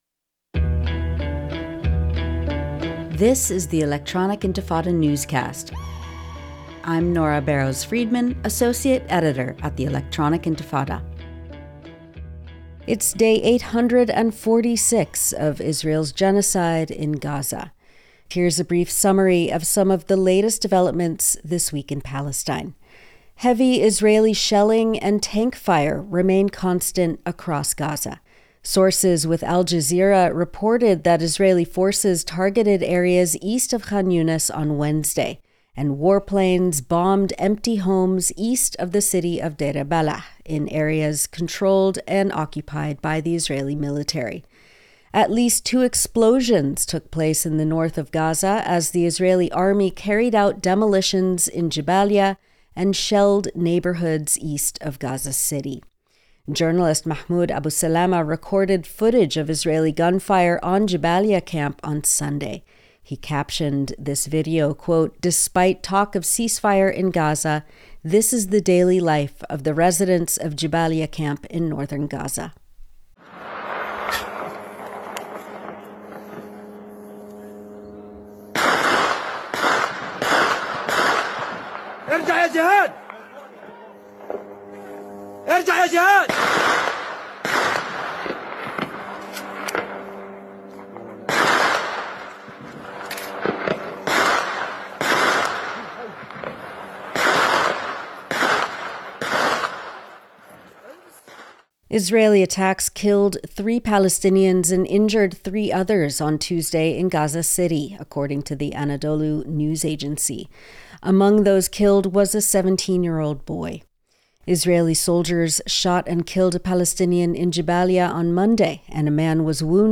Electronic Intifada Newscast 29 January 2026